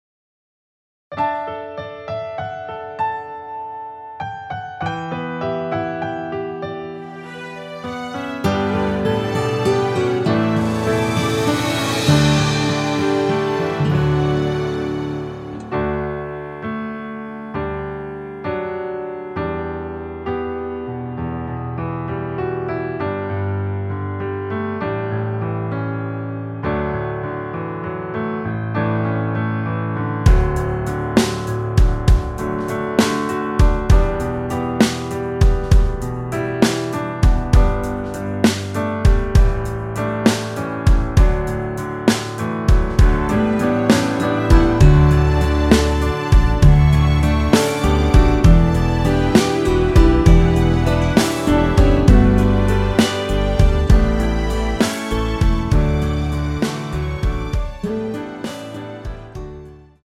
원키에서(-6)내린 짧은 편곡 MR입니다.
앞부분30초, 뒷부분30초씩 편집해서 올려 드리고 있습니다.